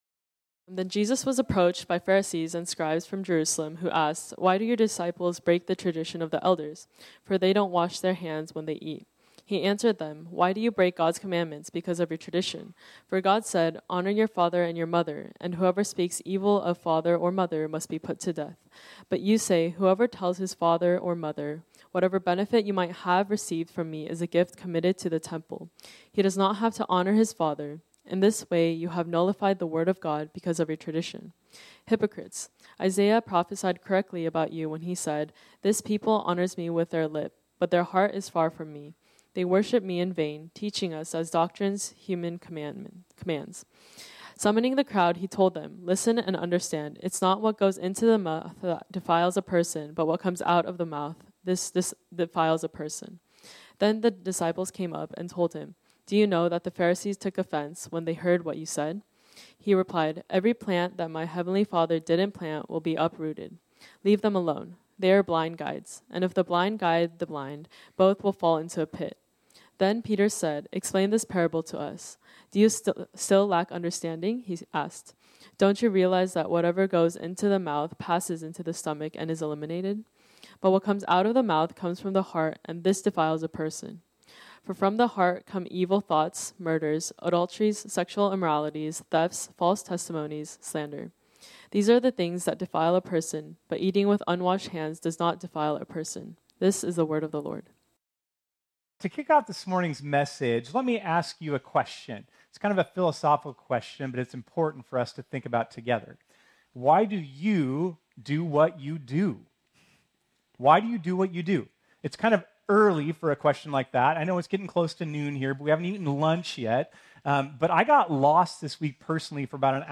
This sermon was originally preached on Sunday, July 7, 2024.